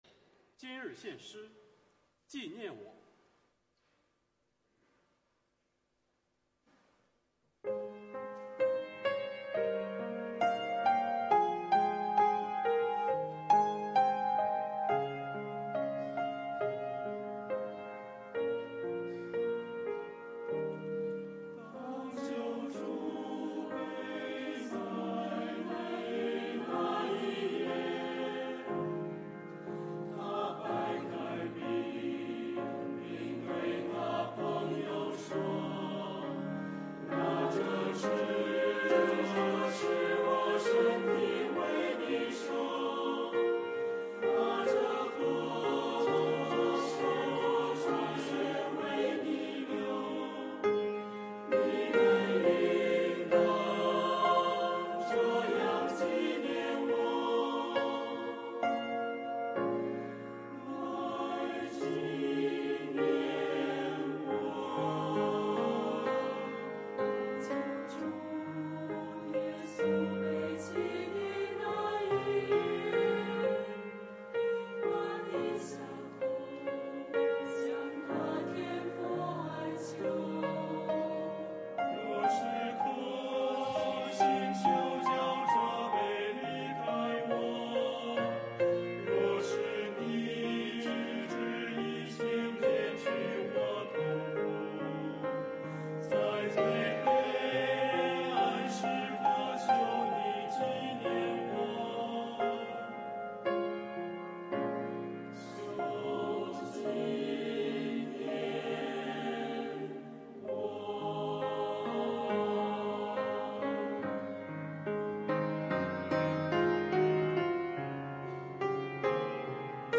诗班献诗